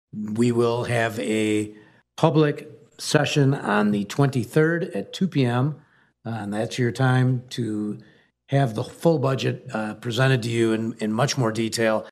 AUDIO: Portage City Manager presents budget proposal
PORTAGE, MI (WKZO AM/FM) – Portage City Manager Patrick McGinnis presented his council with his proposed budget for the next Fiscal year, sending it to them just before last night’s regular meeting.